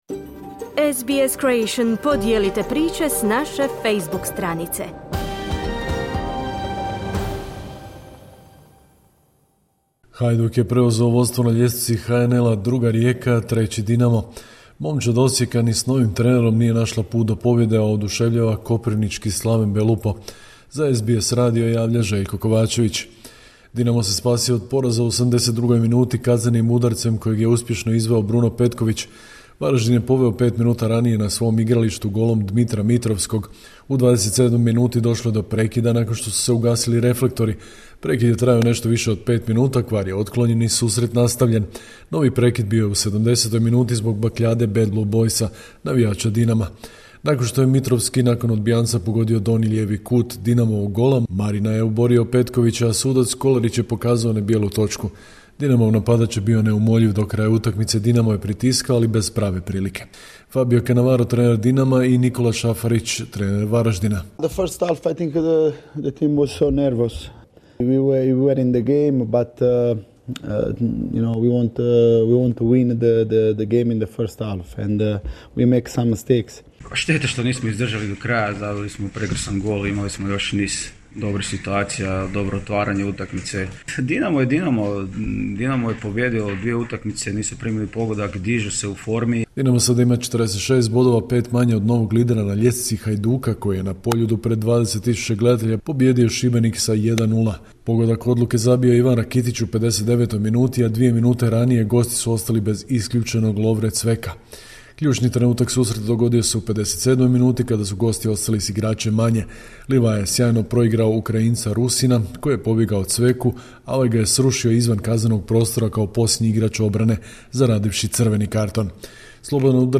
Sportske vijesti, 31.3.2025.